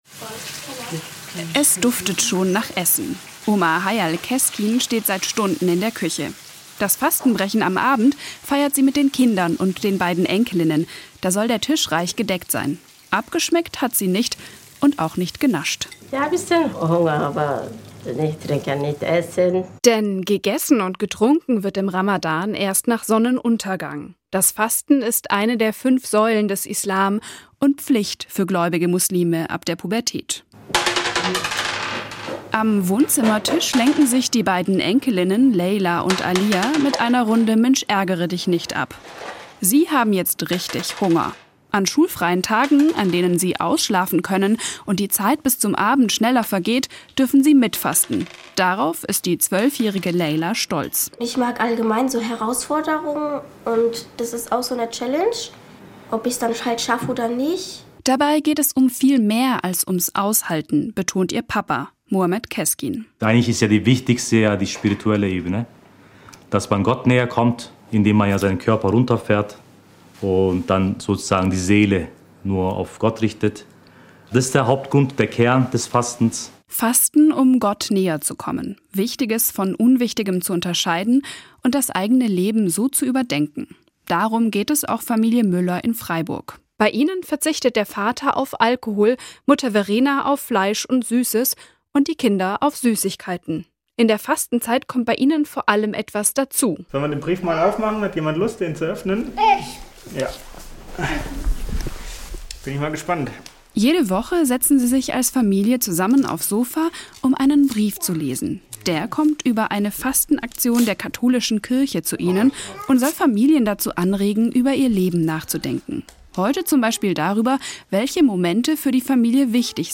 Besuch bei einer christlichen Familie in Freiburg und einer